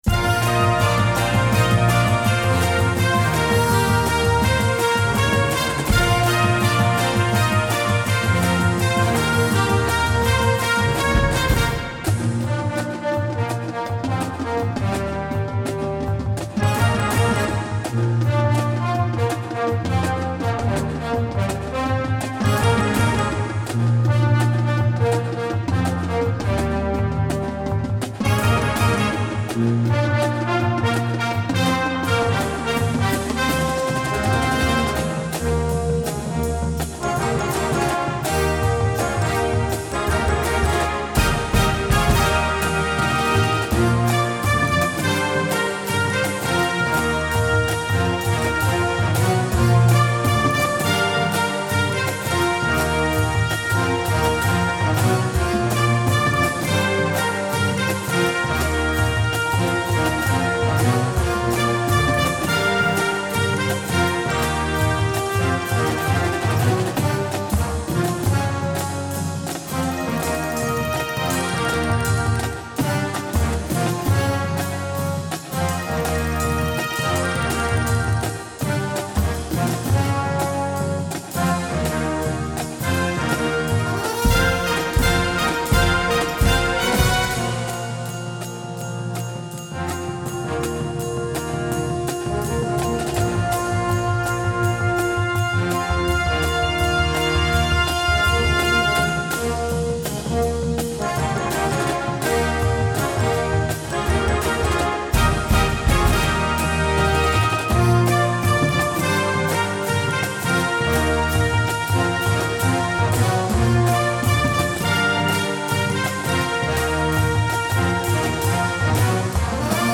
With its frantic pace and driving rhythmic pulse